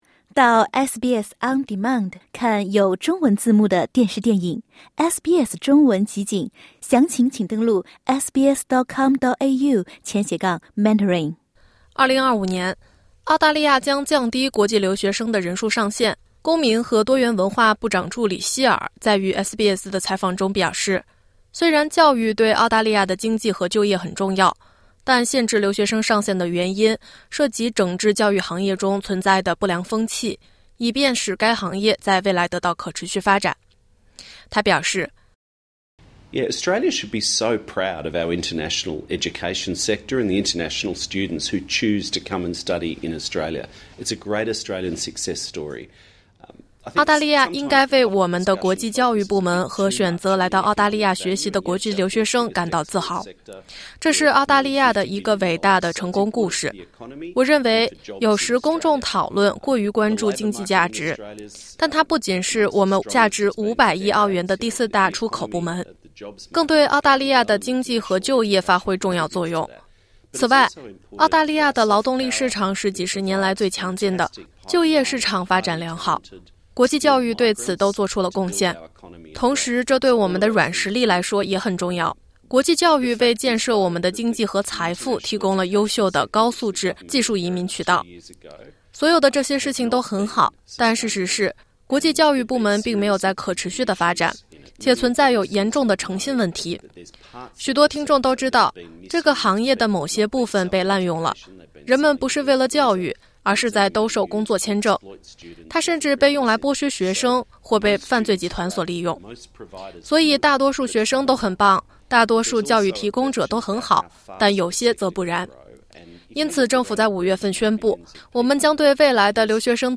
公民和多元文化助理部长希尔（Julian Hill）于本周接受了SBS记者的采访，回答了一系列有关热议的澳洲留学生人数上限、移民入籍考试语言和多元文化差异及代表性的相关问题（点击播客收听详情）。